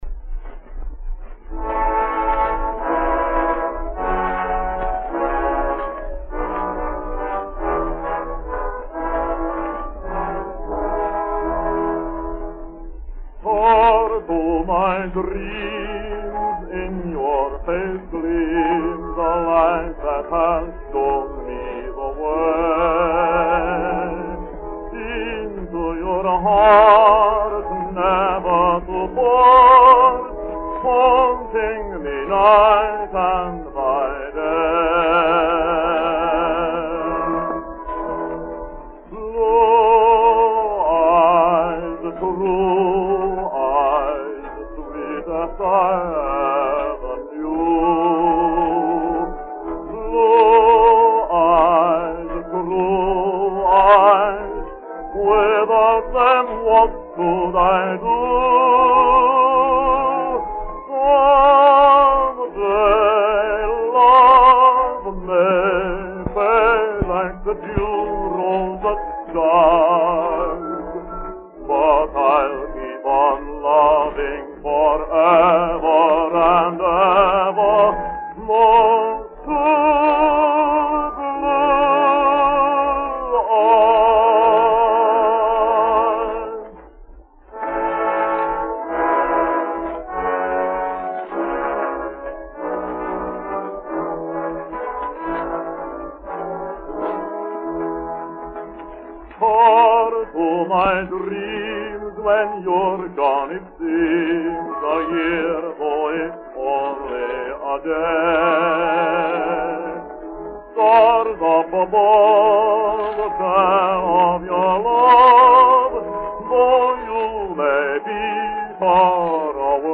Below are some enthusiastic reviews of this popular waltz number.